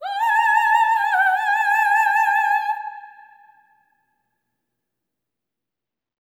OPERATIC09.wav